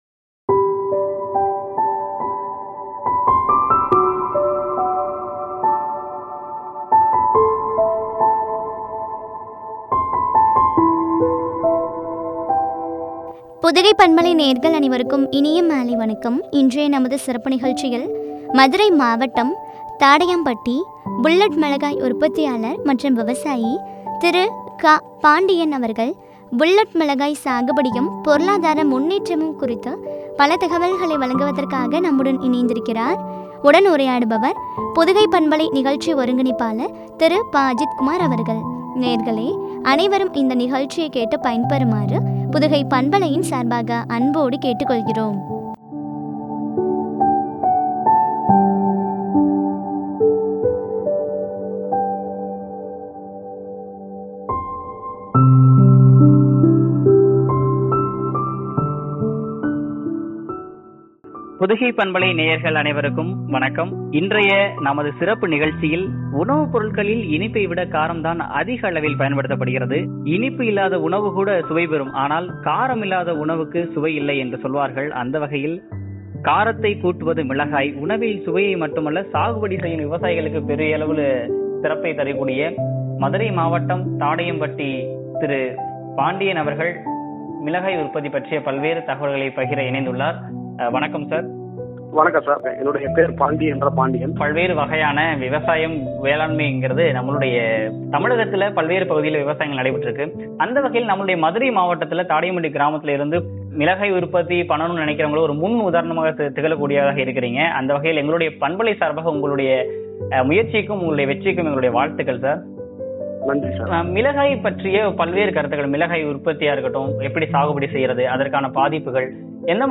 பொருளாதார முன்னேற்றமும் பற்றிய உரையாடல்.